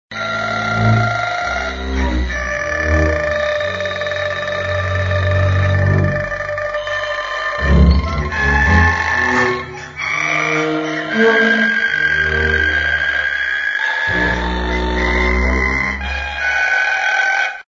Stroh violins, improvised music